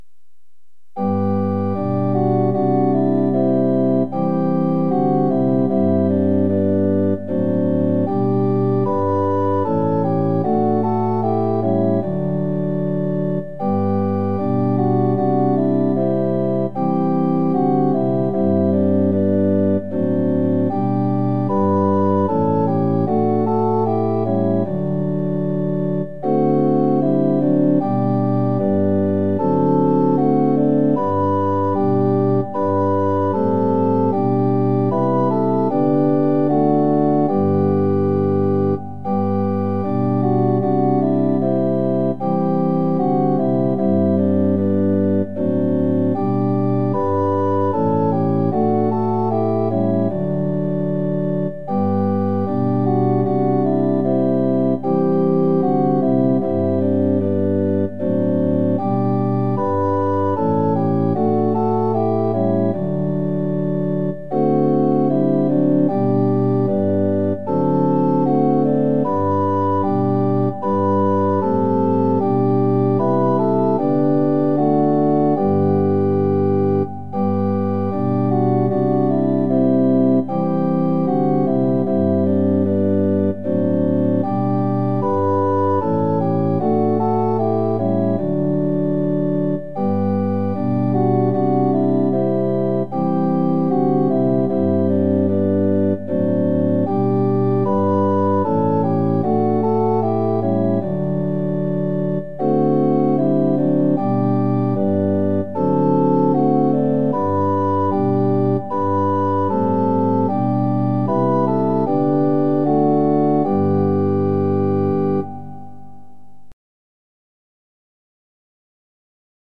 ◆　４分の２拍子：　１拍目から始まります。